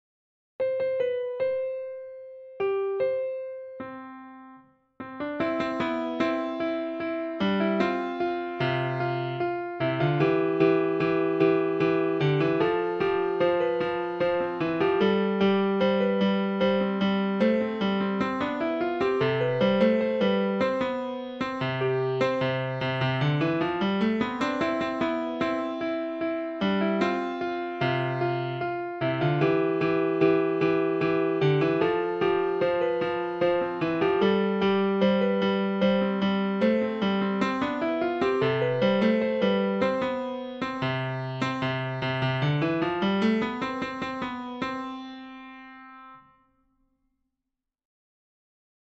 Nursery Rounds: